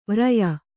♪ vraya